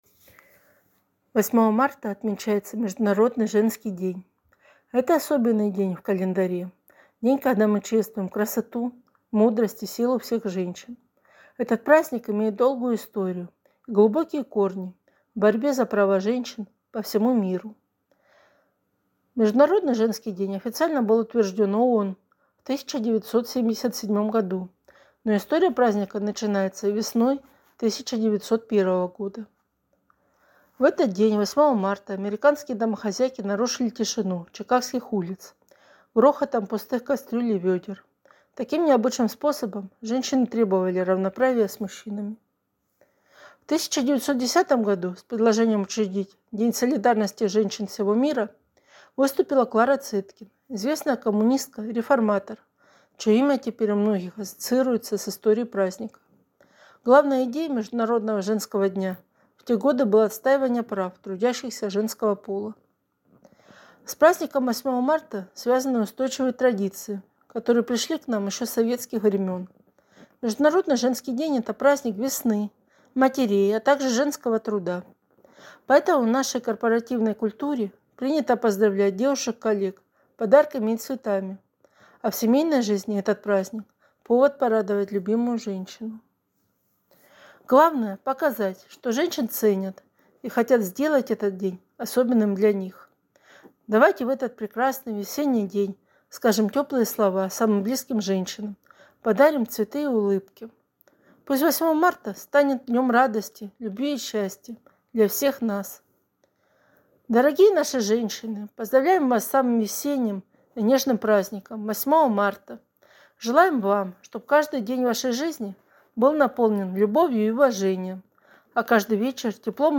К Международному женскому дню внестационарный отдел обслуживания Ростовской библиотеки для слепых подготовил аудиобеседу «